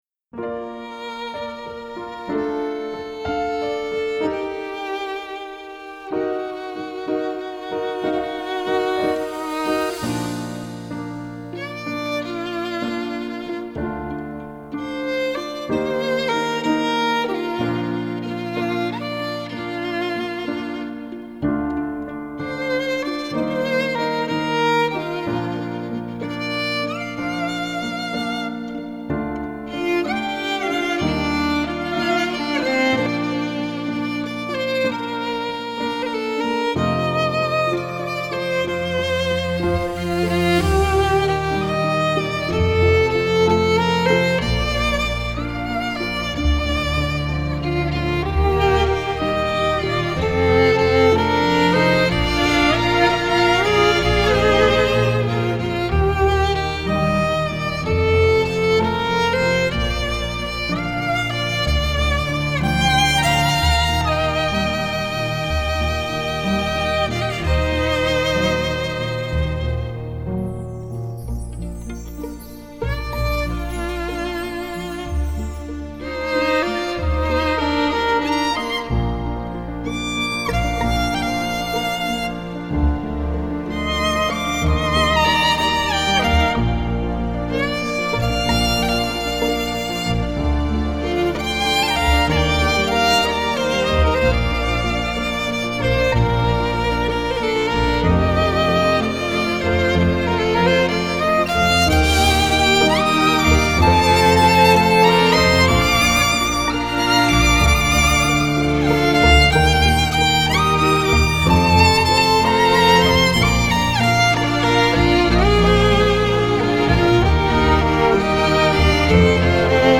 موسیقی کنار تو
آرامش بخش , عاشقانه , موسیقی بی کلام , ویولن
موسیقی بی کلام ویولن